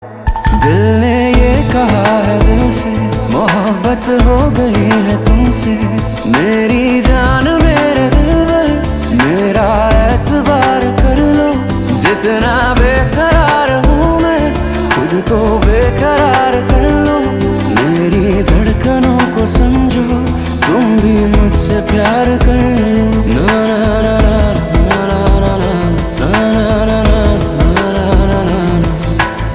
Hindi song